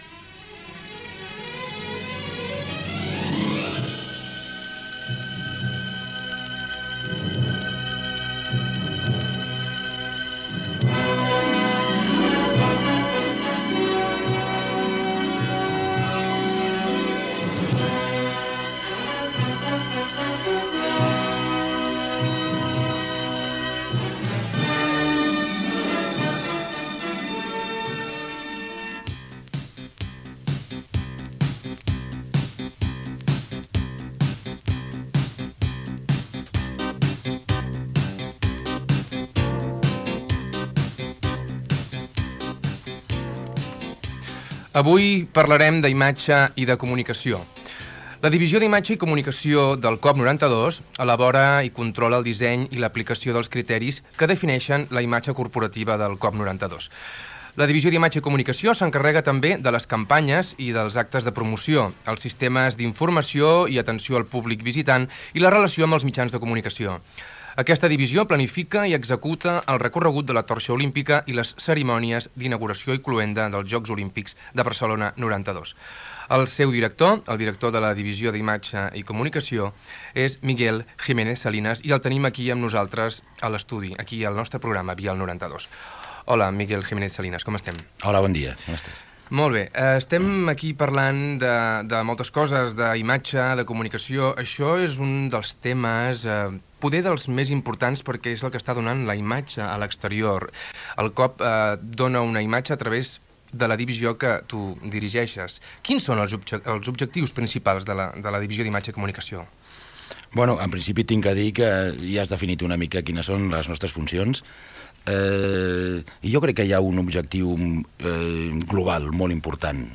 Gènere radiofònic Esportiu